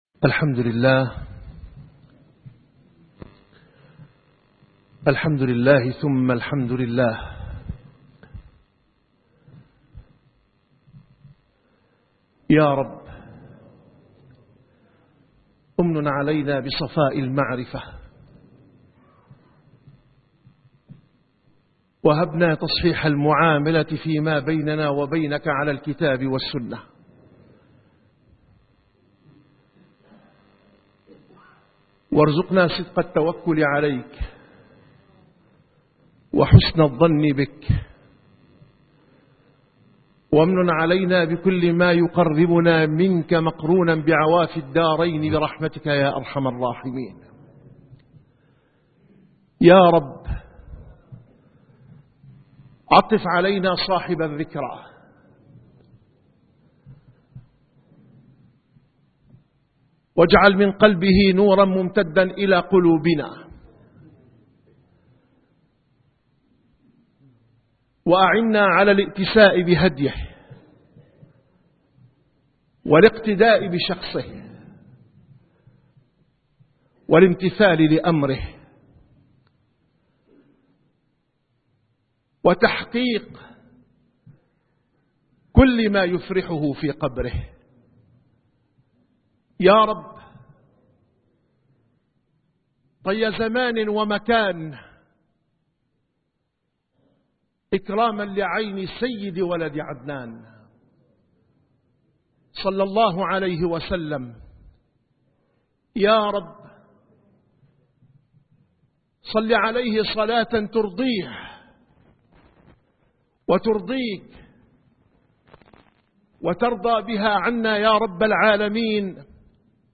- الخطب - خطبة